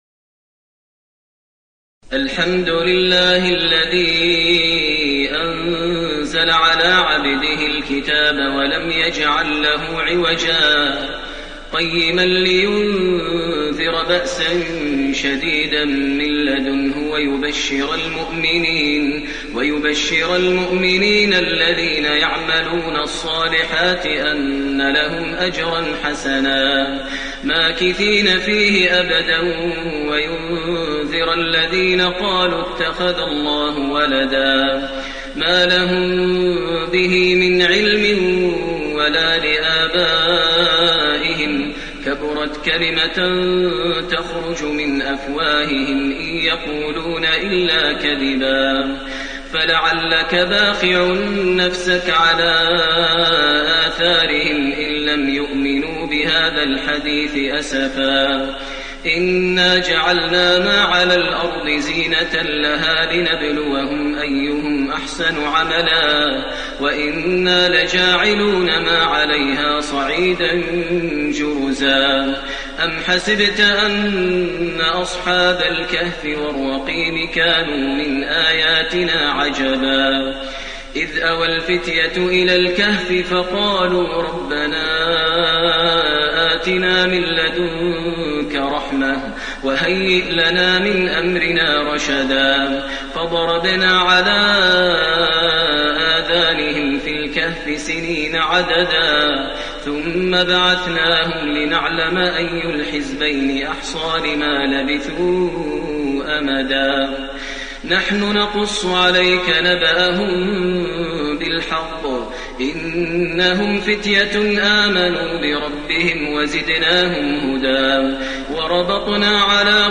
المكان: المسجد النبوي الشيخ: فضيلة الشيخ ماهر المعيقلي فضيلة الشيخ ماهر المعيقلي الكهف